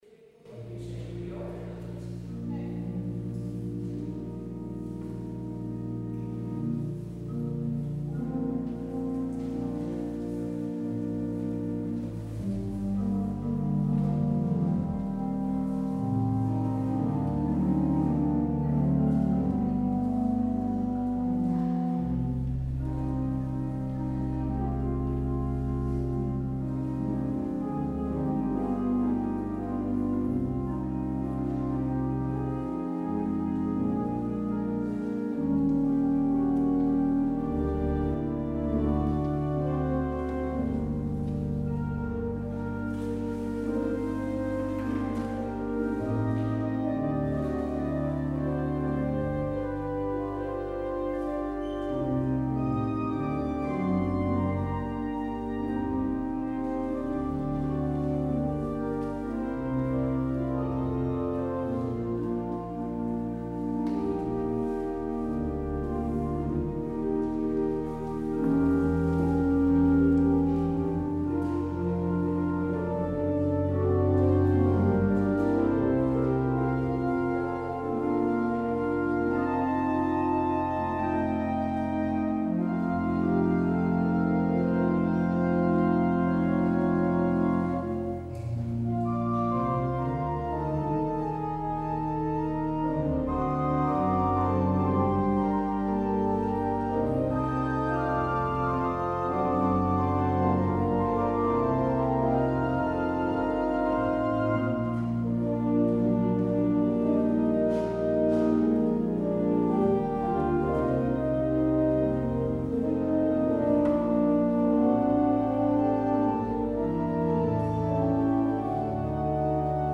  Luister deze kerkdienst hier terug: Alle-Dag-Kerk 8 augustus 2023 Alle-Dag-Kerk https